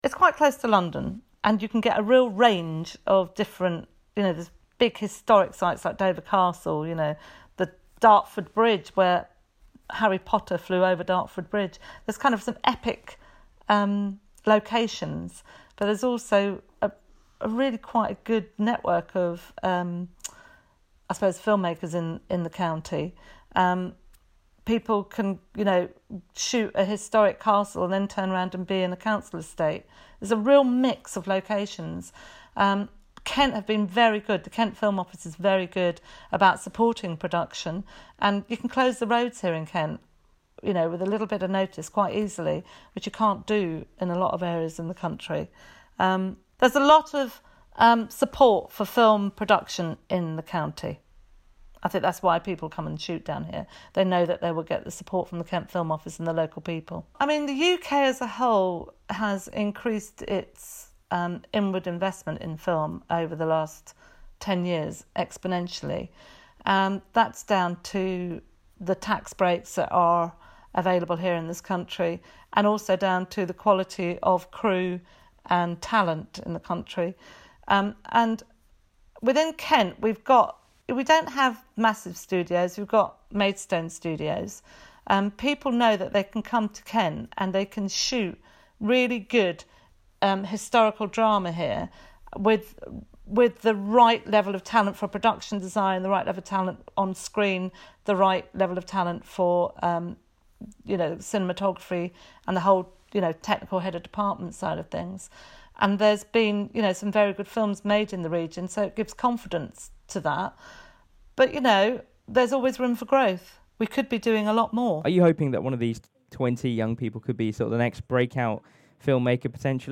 She's told kmfm a bit more about the course.